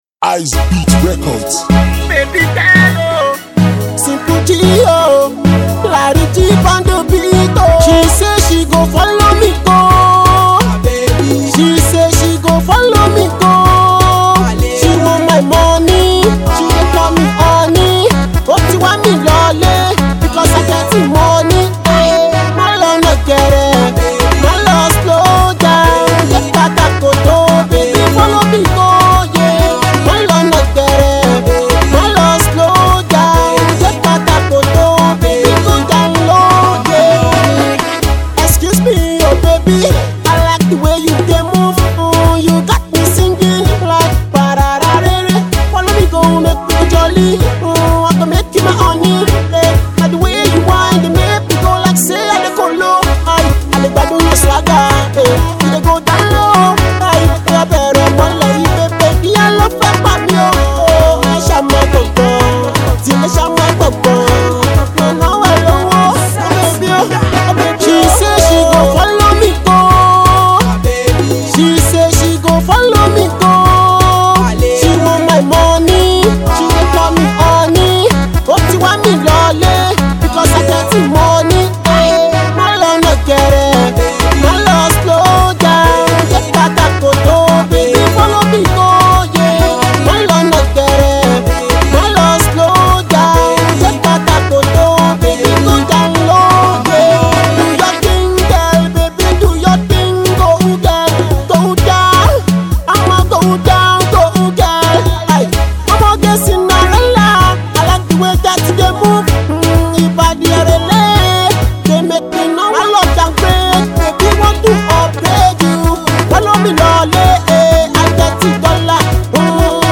mid tempo beat